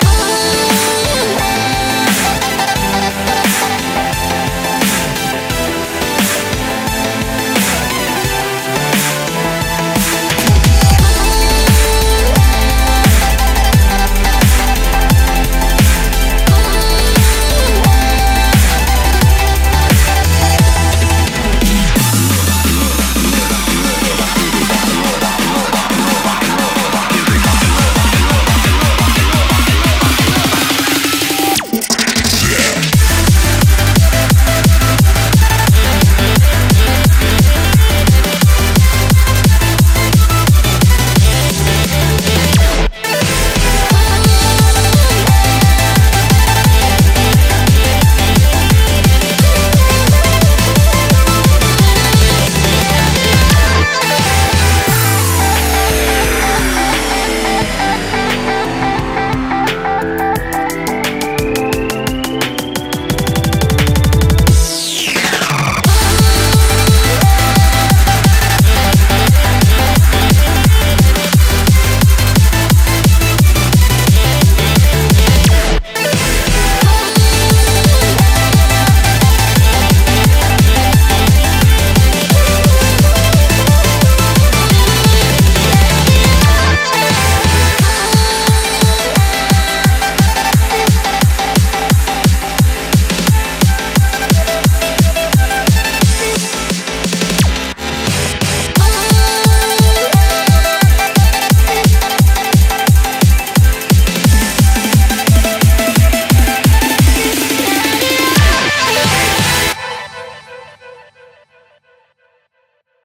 BPM88-175
Audio QualityPerfect (High Quality)
Comments[J-CORE]